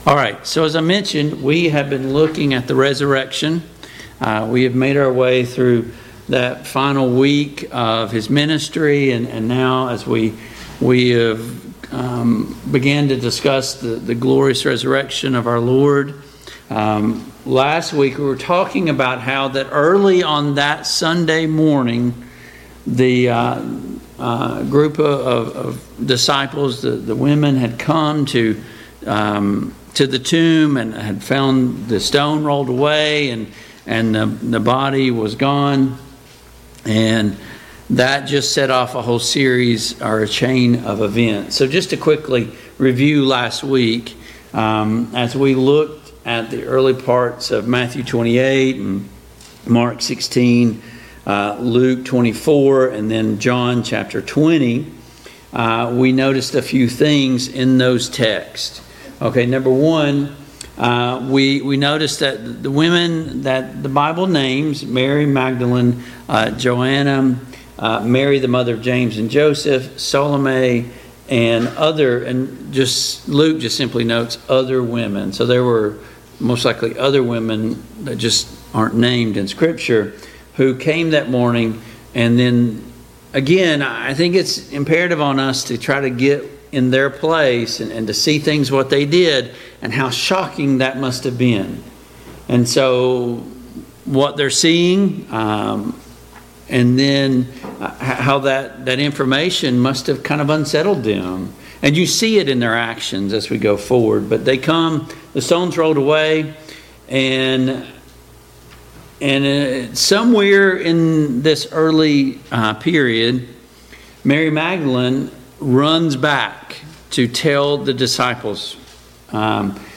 Biblical Timeline of Jesus' Resurrection Service Type: Mid-Week Bible Study Download Files Notes Topics: The Resurrection « India Missions Report 12.